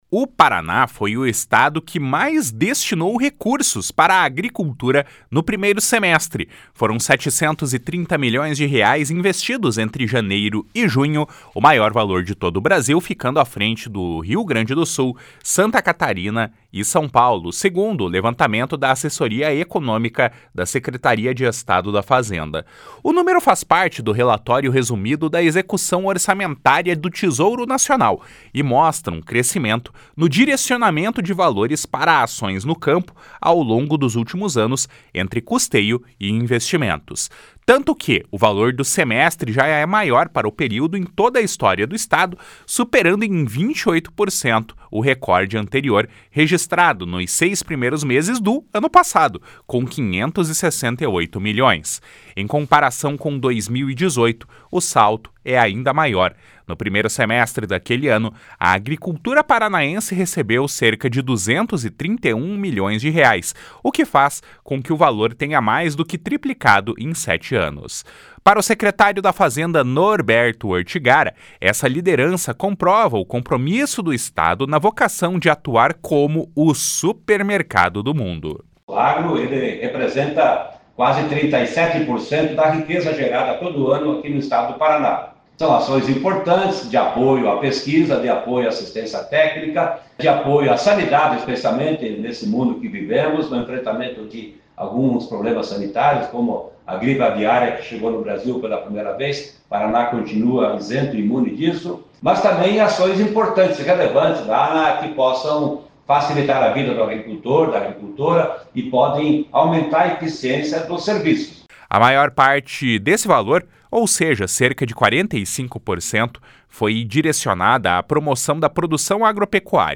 Para o secretário da Fazenda, Norberto Ortigara, essa liderança comprova o compromisso do Estado na vocação de atuar como o supermercado do mundo. // SONORA NORBERTO ORTIGARA //